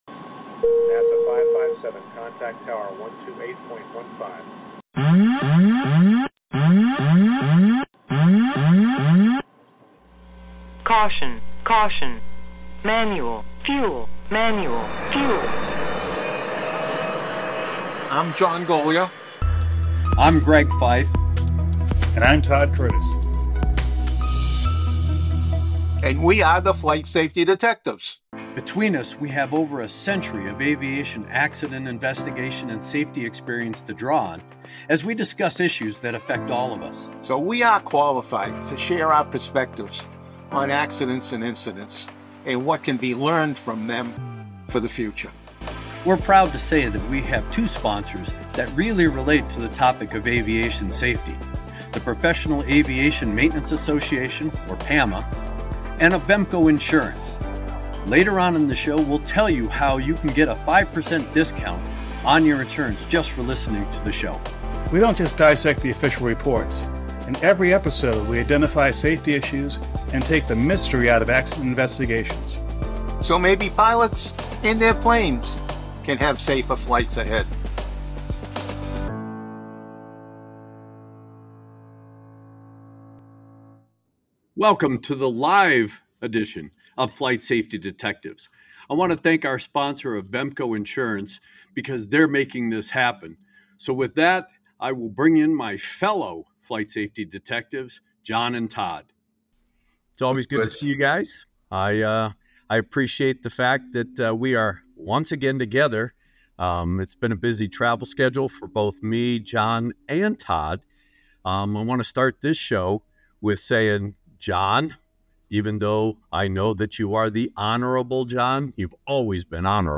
In this recording of the first Flight Safety Detectives live stream